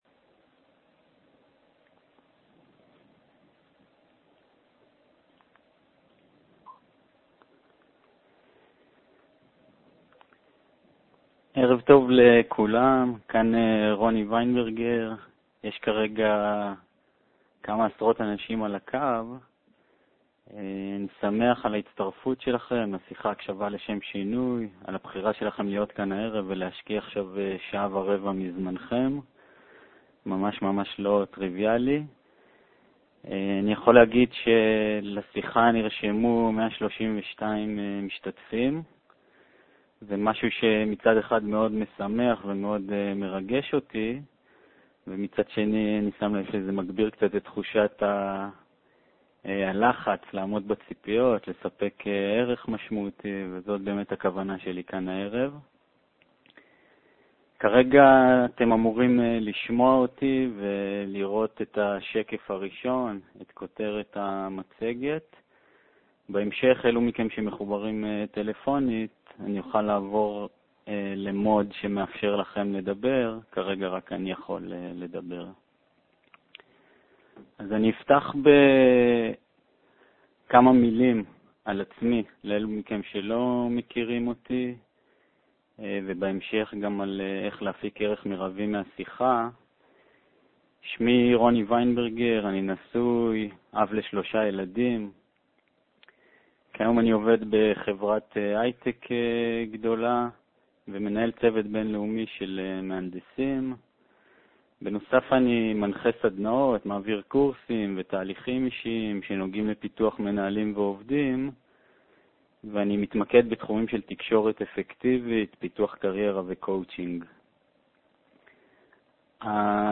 Listening-Webinar-2012.mp3